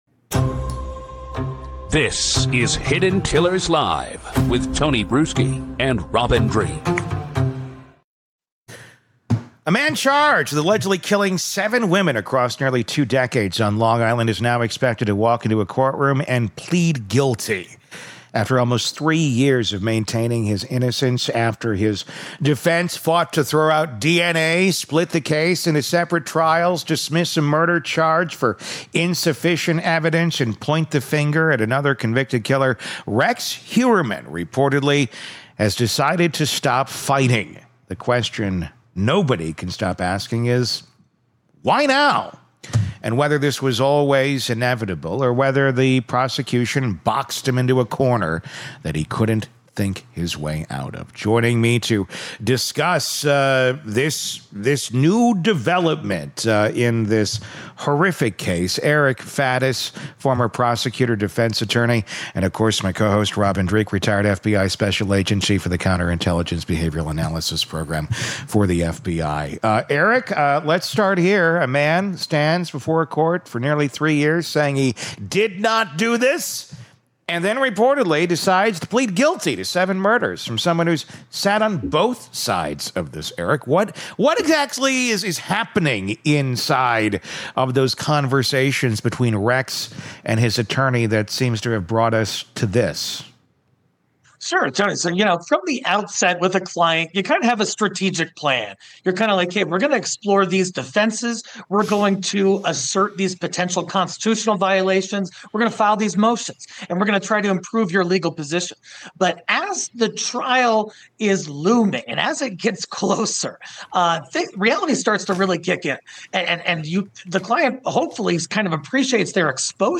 Former felony prosecutor and defense attorney